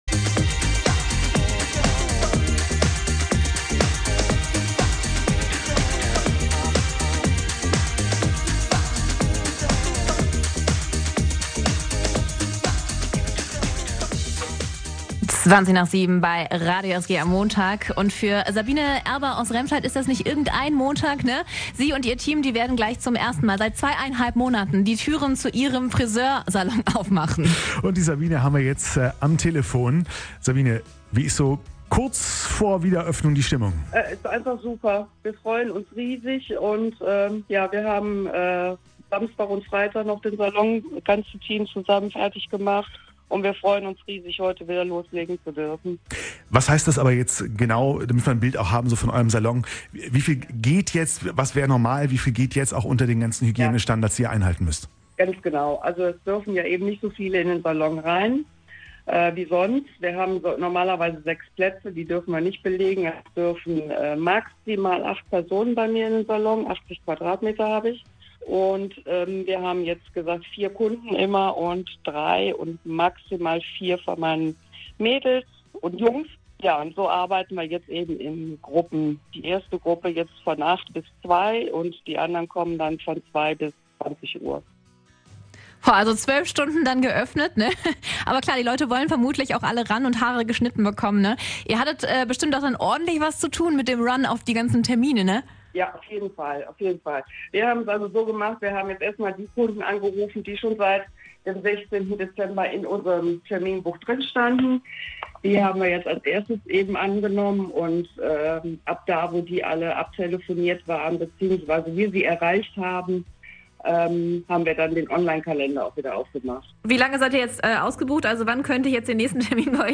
haben vorab in der Morgenshow mit ein paar von ihnen telefoniert.